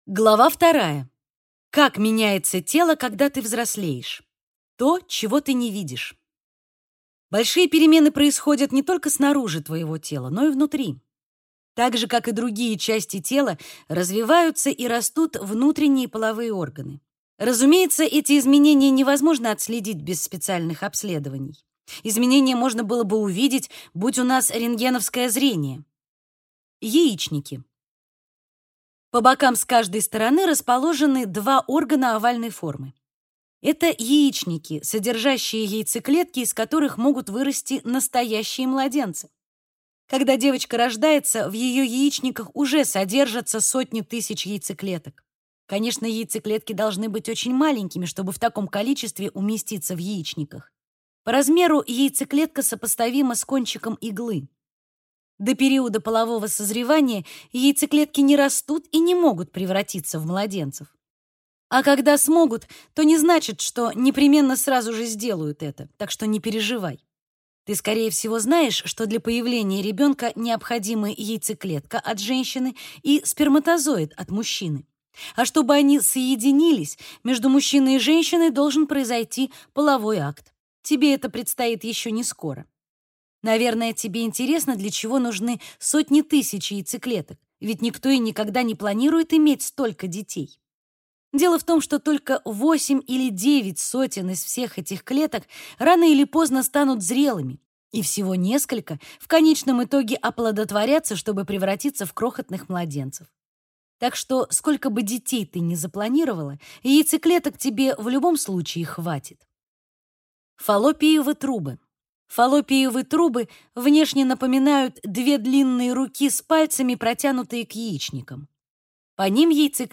Аудиокнига Девчонкам о важном. Все, что ты хотела знать о взрослении, месячных, отношениях и многом другом | Библиотека аудиокниг